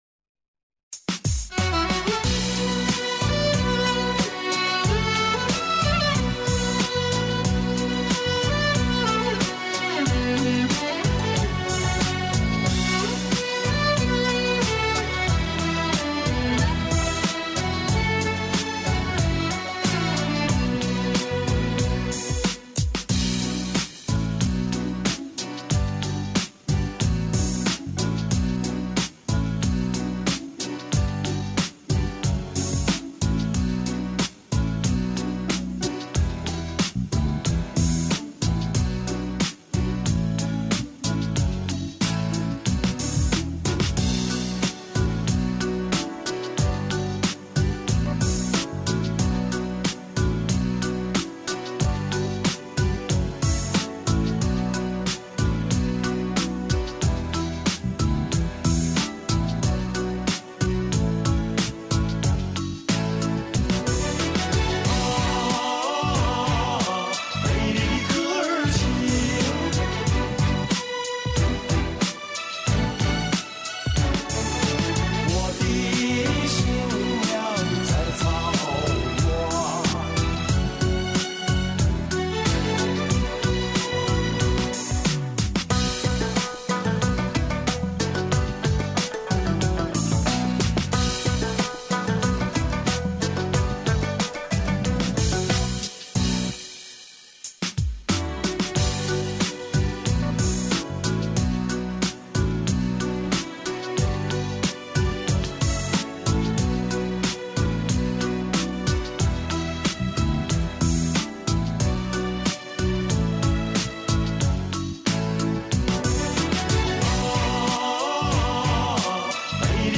调式 : G=1